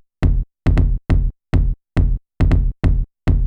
THUMB BASS-L.wav